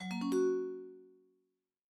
Play, download and share IceCreamNotify original sound button!!!!